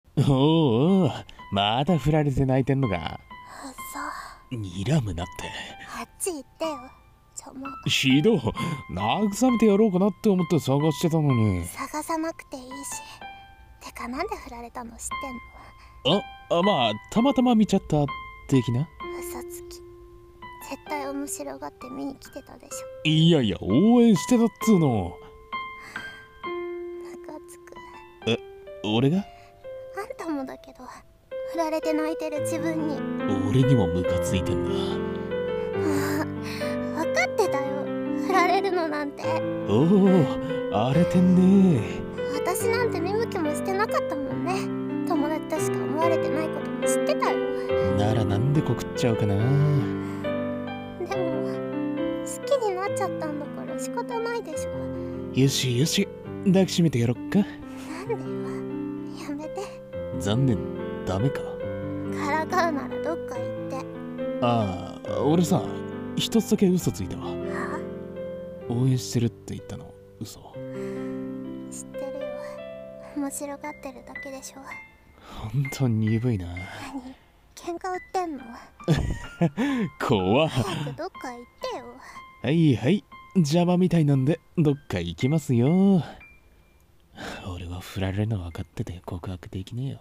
二人声劇】カタオモイ